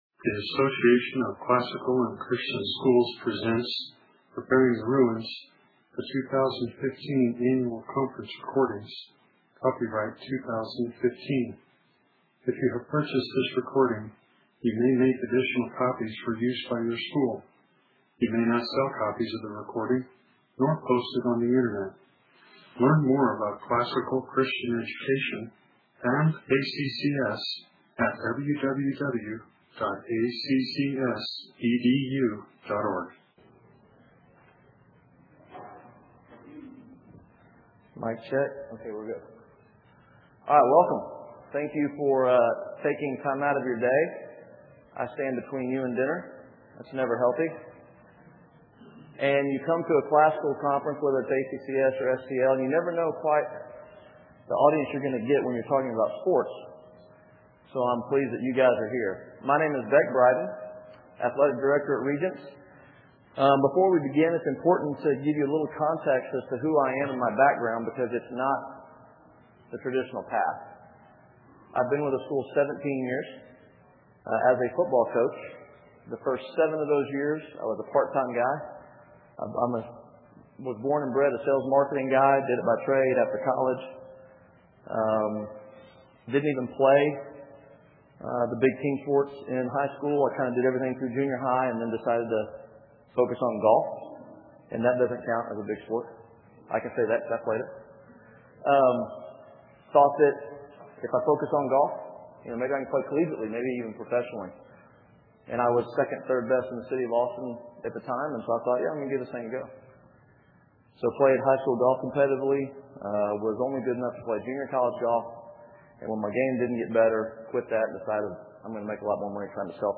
2015 Workshop Talk | 1:03:39 | All Grade Levels, Athletics & Extracurricular